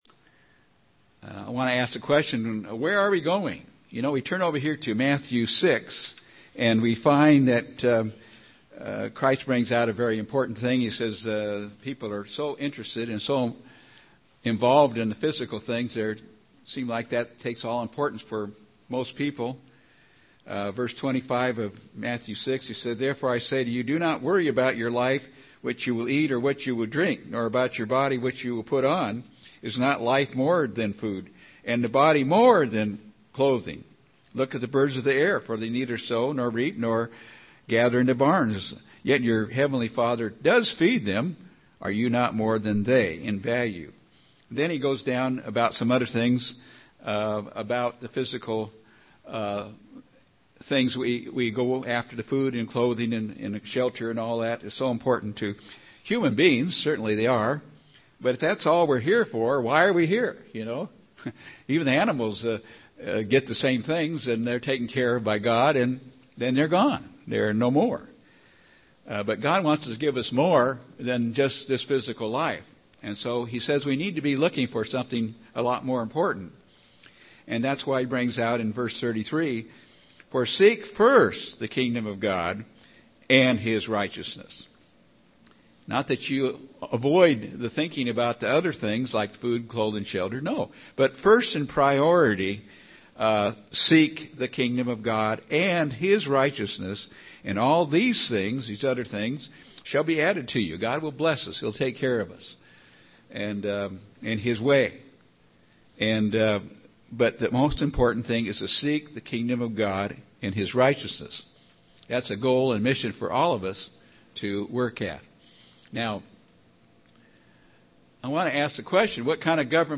9-17-11 sermon.mp3